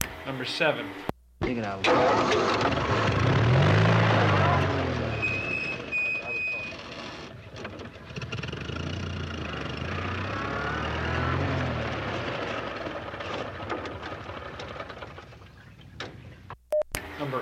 汽车吱吱声椅子
描述：一个坐在办公椅上的人的录音，模拟汽车中的颠簸路面，
标签： 吱吱声 吉普车 磕碰 椅子 officechair 道路颠簸
声道立体声